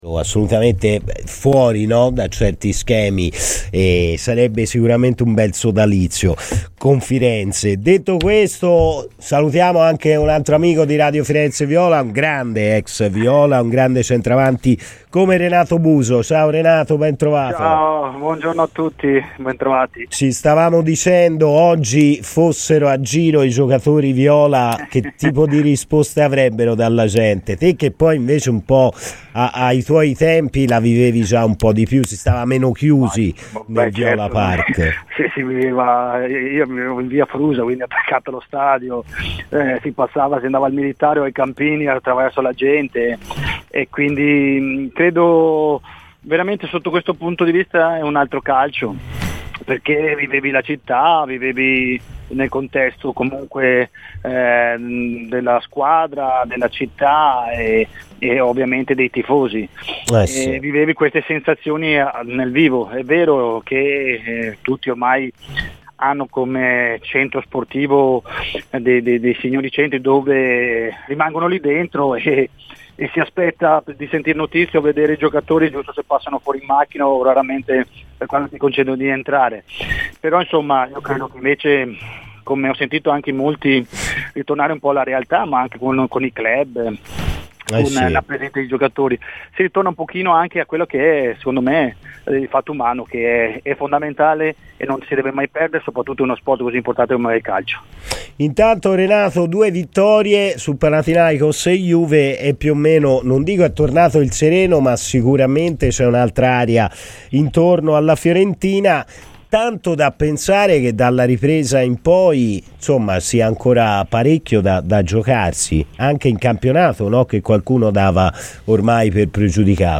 Renato Buso, ex viola, intervenuto a Radio FirenzeViola, durante 'Palla al Centro', ha detto la sua sul momento della Fiorentina: "La partita con la Juve era stata vista come la svolta per rimanere attaccati a quelle sopra.